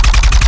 GUNAuto_RPU1 B Loop_01_SFRMS_SCIWPNS.wav